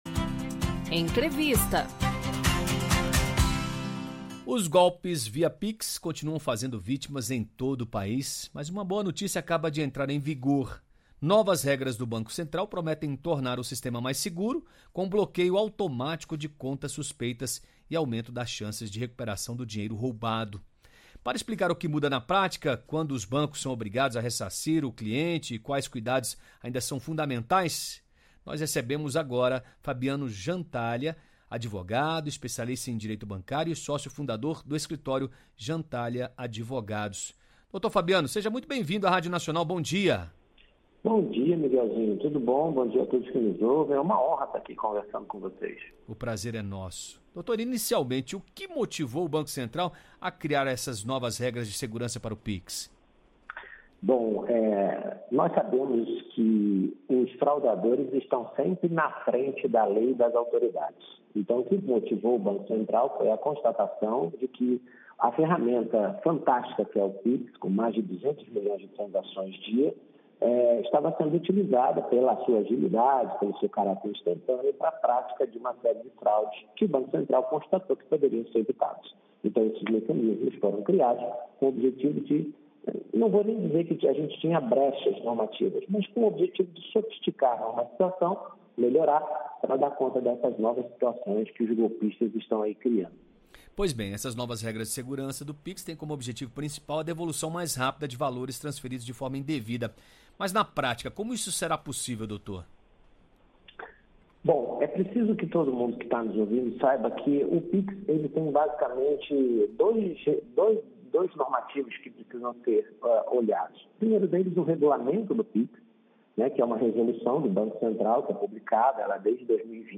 Em entrevista à Rádio Nacional